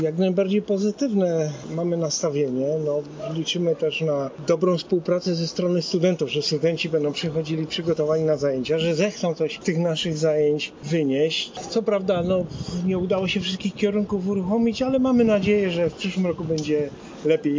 Mieliśmy również okazję porozmawiać ze studentami o ich wrażeniach po pierwszych dniach na uniwersytecie, jakie są ich plany, oczekiwania i obawy na nadchodzący rok: